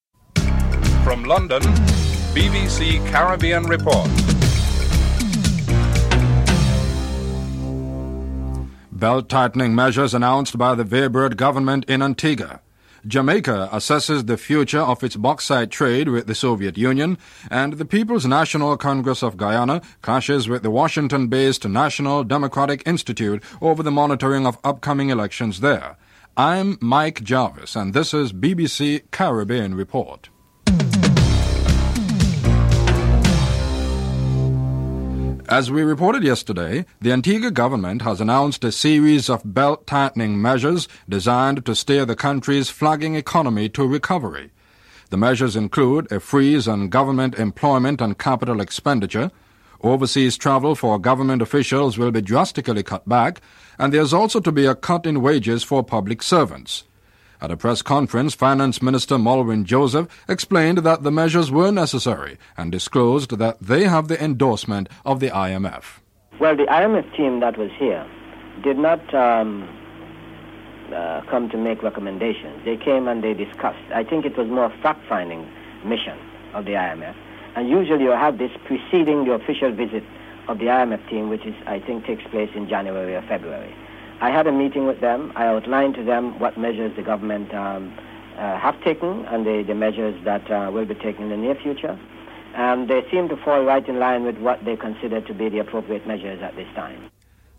1. Headlines (00:00-00:34)